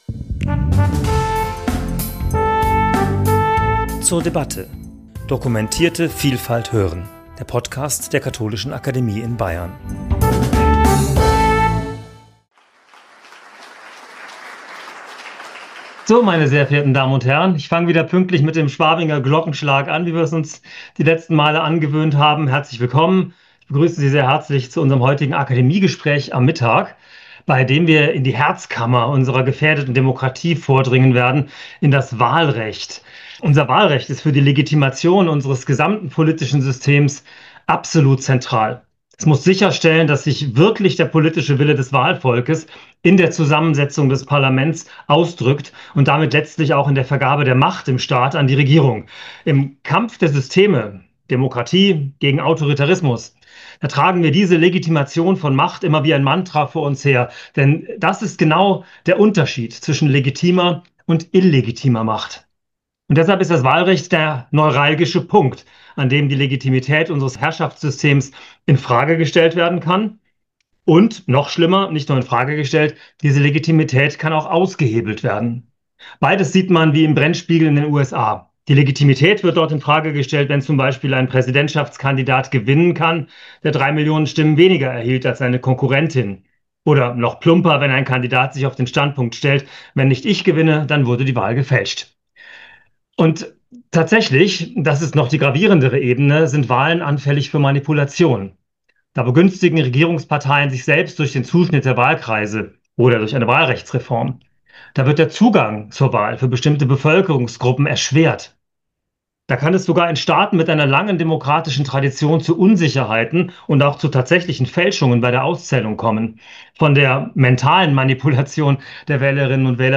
Gespräch zum Thema 'Gibt es überhaupt ein gerechtes Wahlrecht? Von Tücken und Tricks beim Ankreuzen' ~ zur debatte Podcast